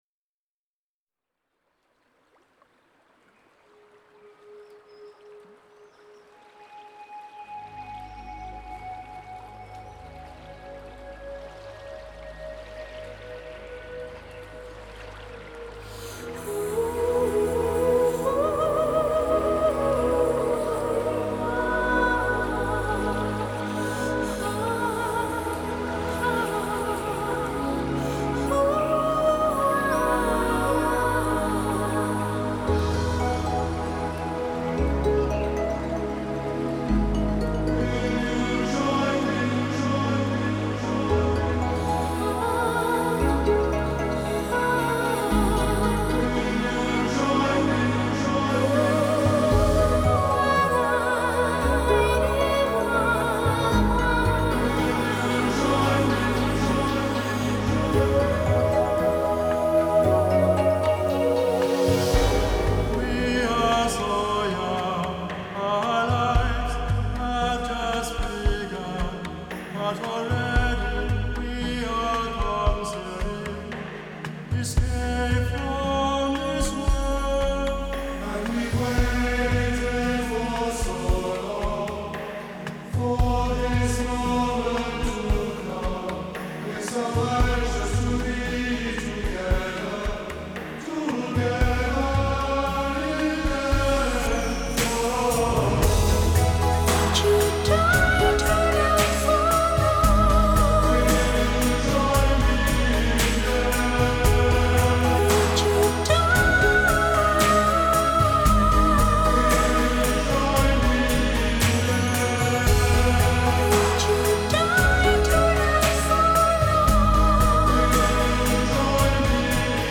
Genre: Pop, Rock.